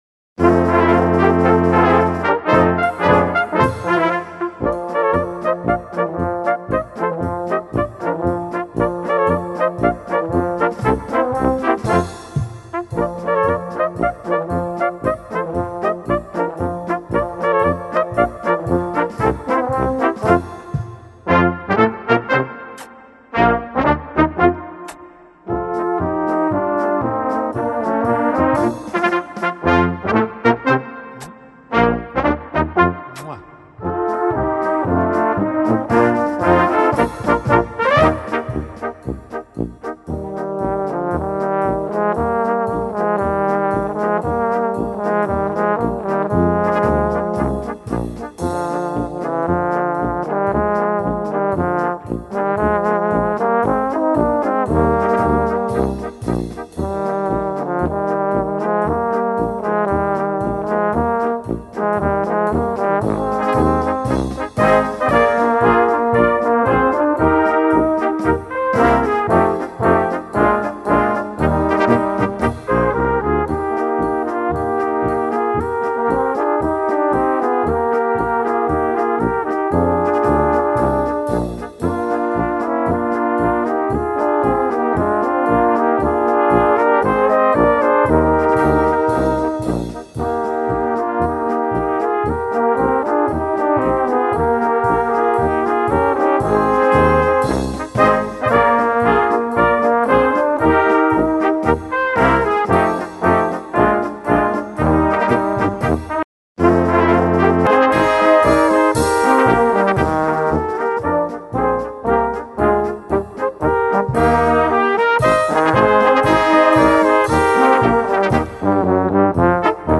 Kategorie Blasorchester/HaFaBra
Unterkategorie Polka
Besetzung Ha (Blasorchester)